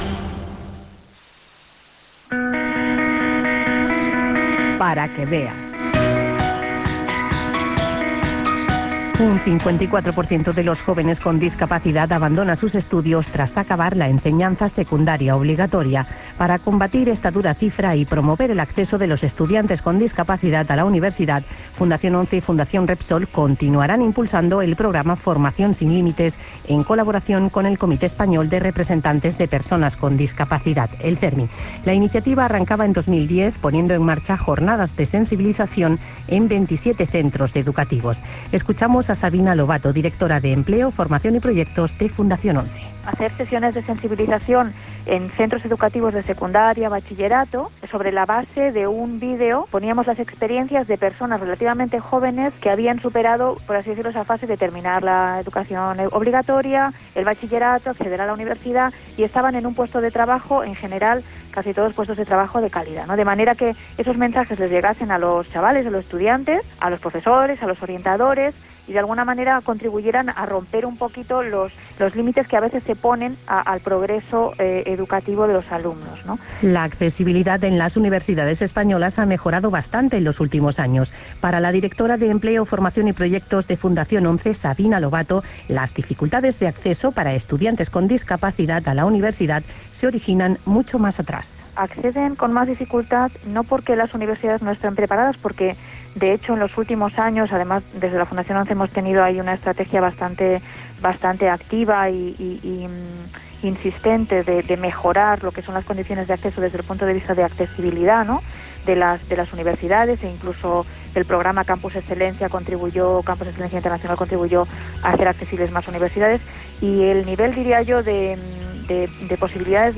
campus_inclusivos-_radio_5.wav